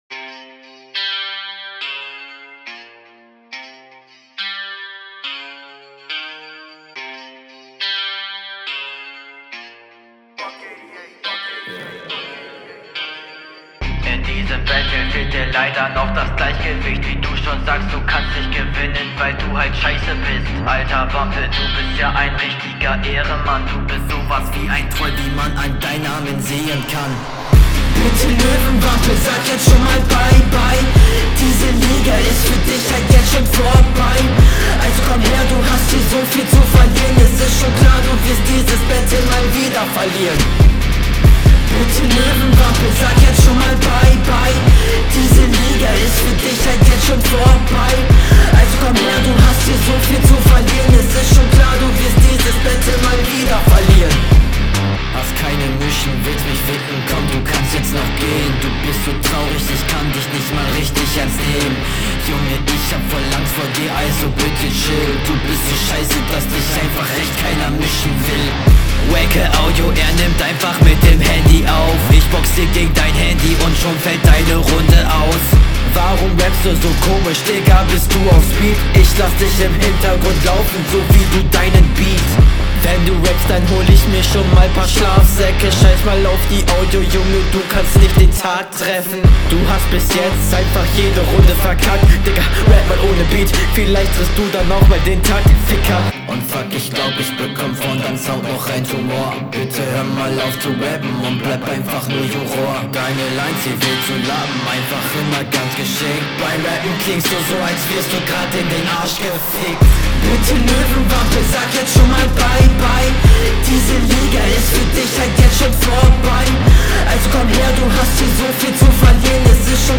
Flow: ist sehr gut kommt auf dem beat sehr gut klar gibt keine flow fehler …
Flow: Also der Beat ist gar nicht meins und wie du auf ihm Flowst halt …